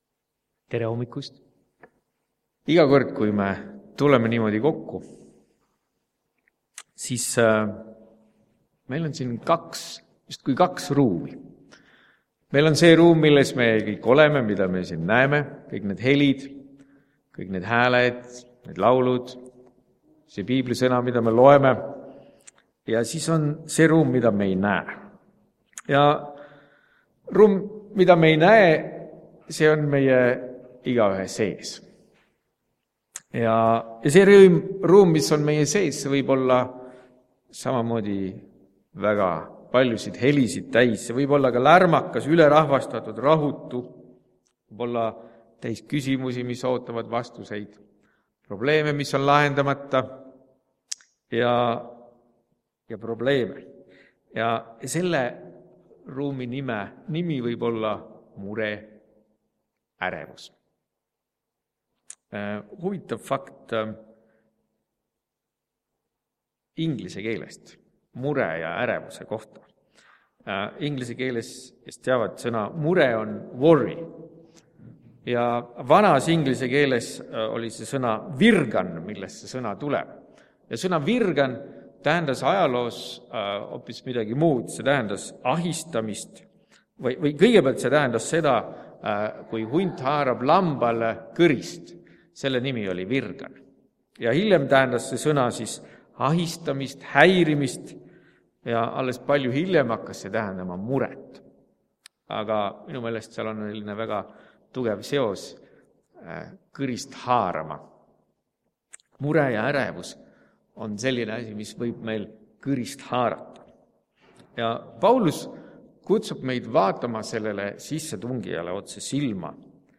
Tänases jutluses räägime ärevusest ja sellest, kuidas leida väljapääsu, kuidas leida rahu hingele. RUUM MEIE SEES Muusikaliseks hetkeks laul aastast 1980. Tallinna kvartett ja kandled - Jeesus Sinu sarnaseks saada
Jutlused